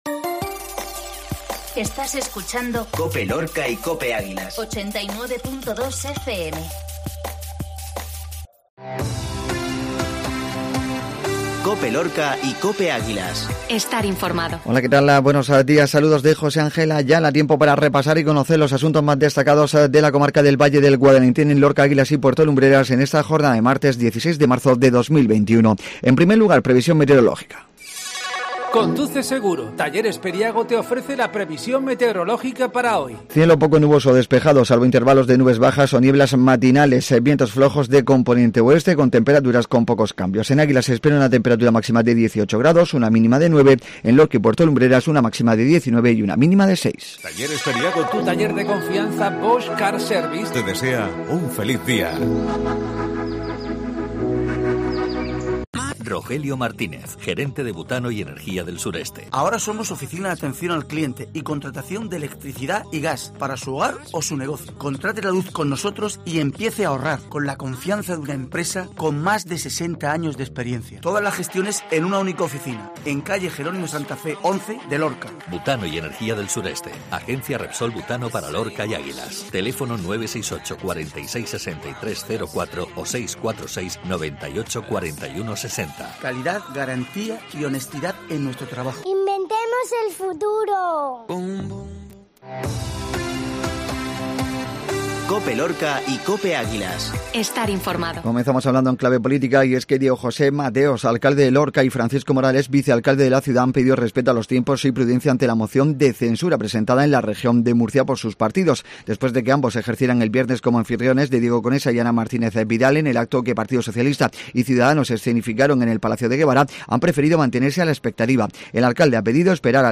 INFORMATIVO MATINAL MARTES